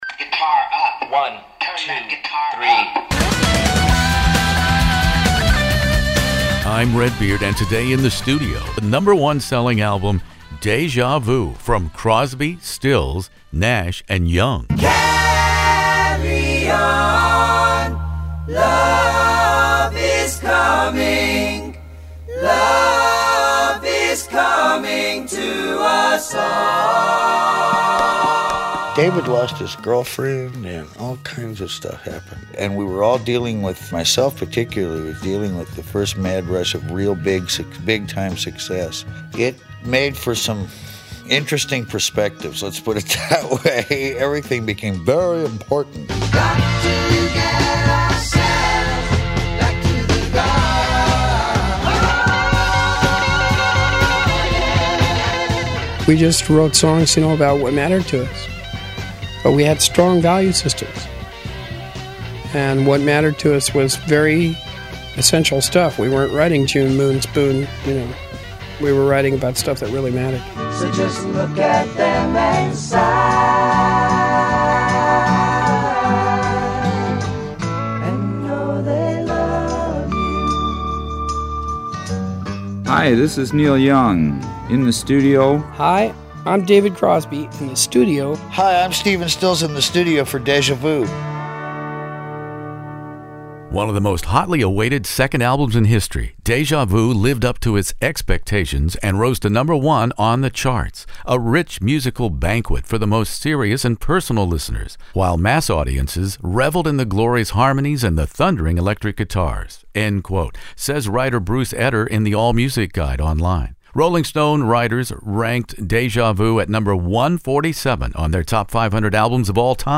As you hear during this In The Studio Crosby, Stills, Nash, and Young classic rock interview regarding making Déjà Vu, rock myth collides head-on with fact regarding Neil Young’s initial integration into Crosby, Stills, and Nash.